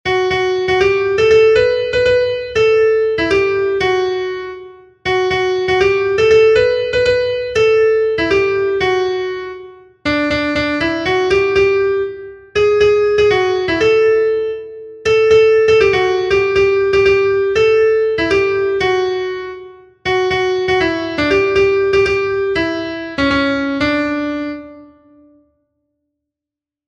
Kontakizunezkoa
Hamarreko txikia (hg) / Bost puntuko txikia (ip)
AABDE